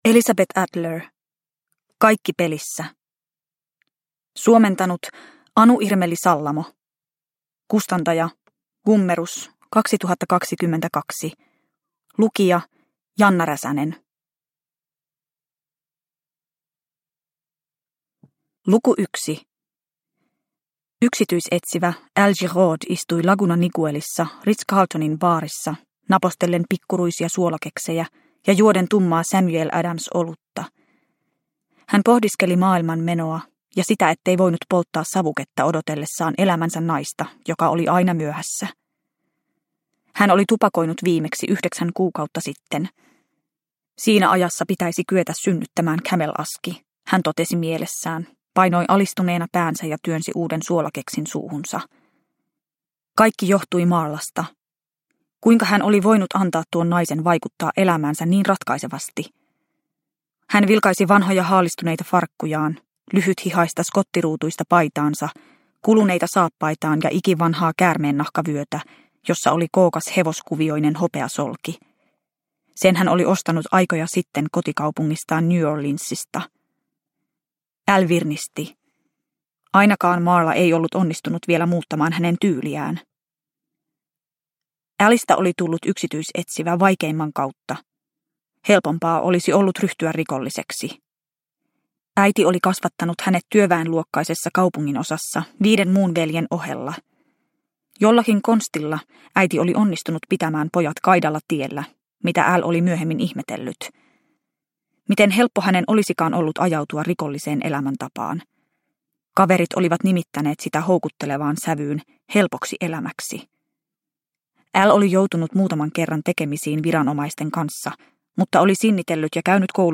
Kaikki pelissä – Ljudbok – Laddas ner